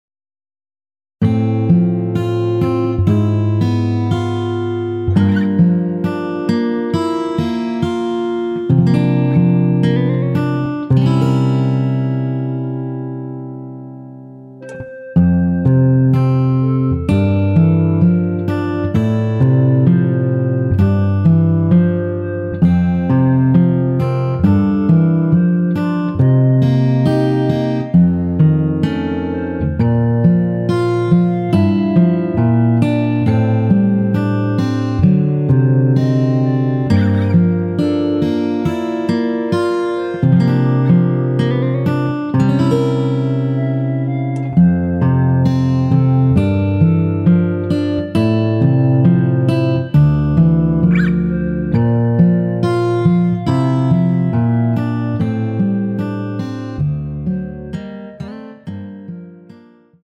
원키에서(-2)내린 멜로디 포함된 MR입니다.
앨범 | O.S.T
앞부분30초, 뒷부분30초씩 편집해서 올려 드리고 있습니다.
중간에 음이 끈어지고 다시 나오는 이유는
(멜로디 MR)은 가이드 멜로디가 포함된 MR 입니다.